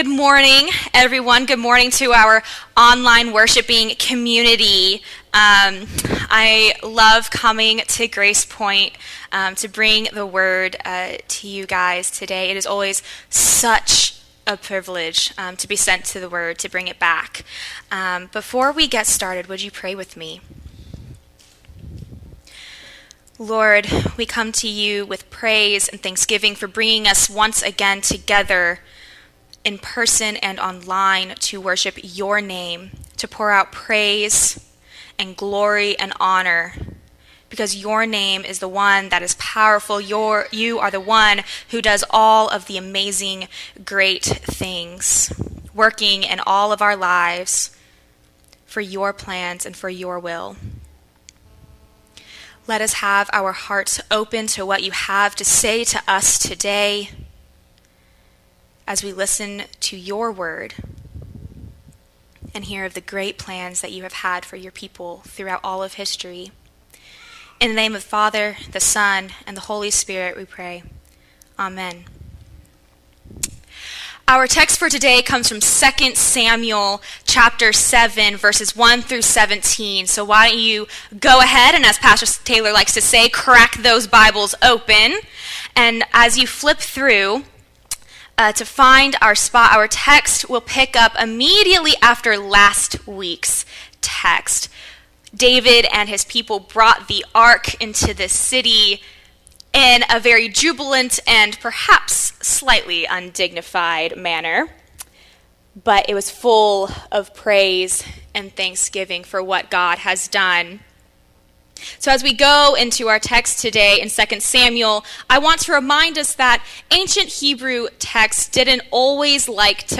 Sermons Archive - GracePoint at Mt. Olive